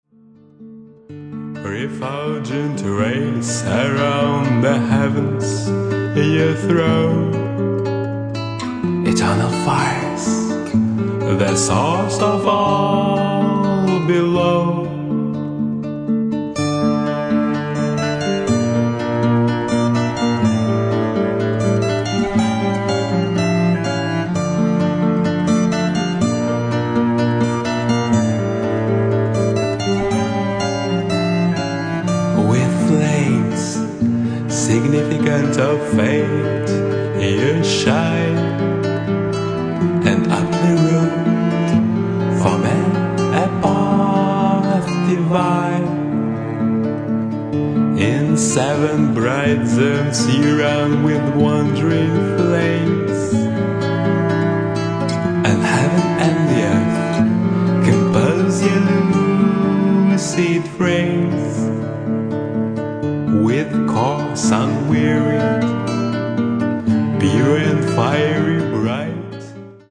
夜の帳が降りてきて...優美なファンタジー世界が広がる
Cello
Flute, Tin Whistle, Recorder
Violin